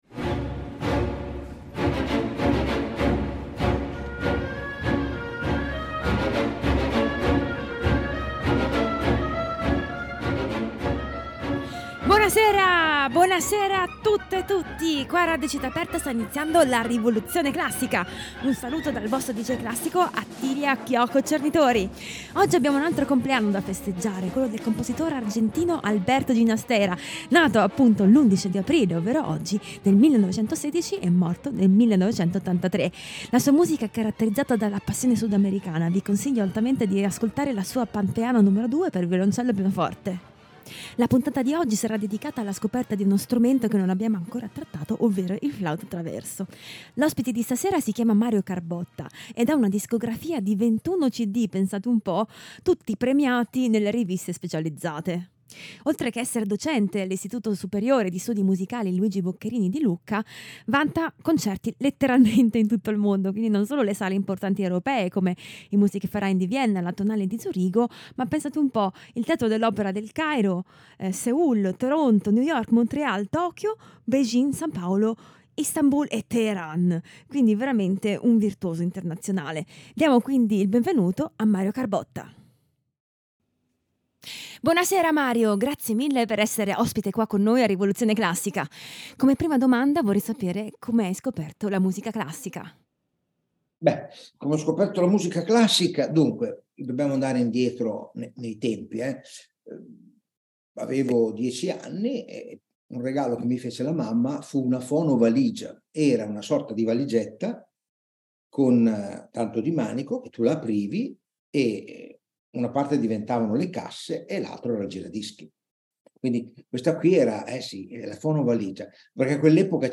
Ospite di questa puntata il flautista
dal Quartetto per flauto, violino, viola e chitarra
dal Quintetto per flauto, oboe, viola, violoncello e arpa